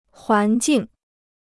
环境 (huán jìng) Free Chinese Dictionary